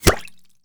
bullet_impact_water_03.wav